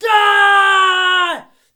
battle-cry-5.ogg